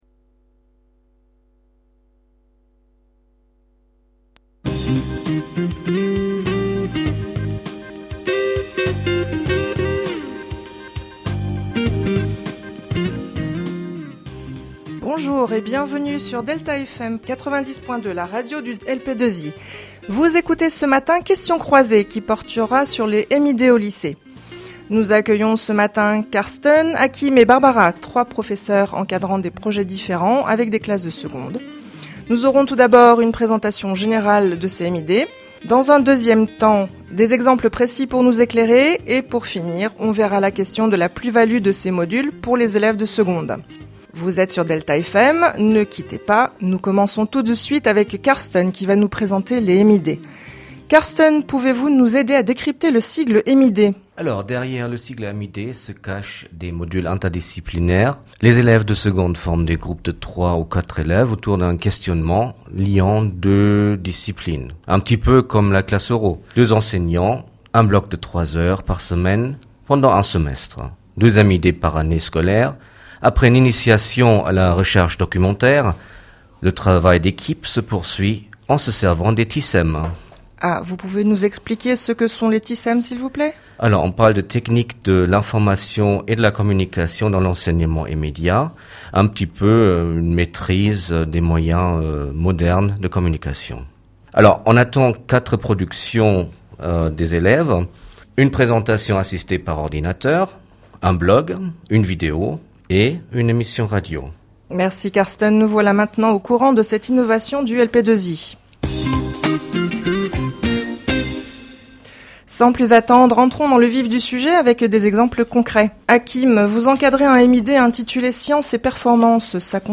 Dans ce cadre, ils ont été amenés à créer des émissions de radio sur le thème des MID.